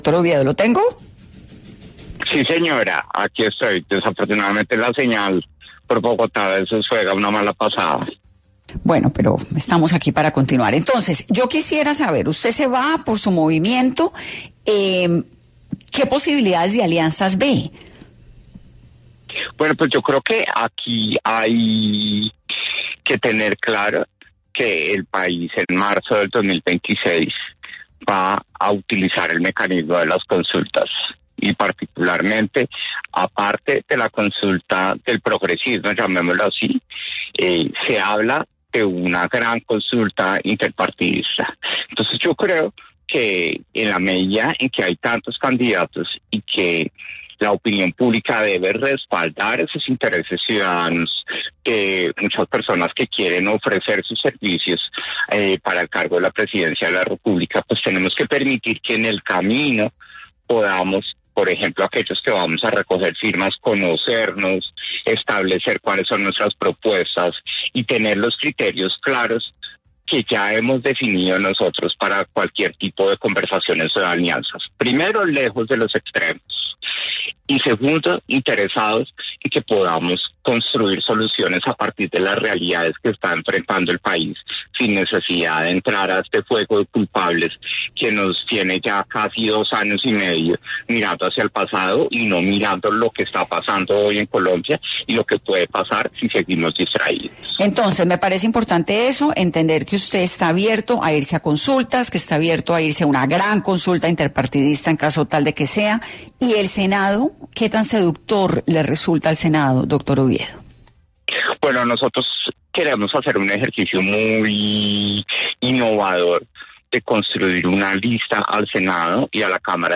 En 10AM de Caracol Radio, estuvieron diferentes precandidatos presidenciales para hablar sobre sus procesos e ideas de cara al siguiente año electoral.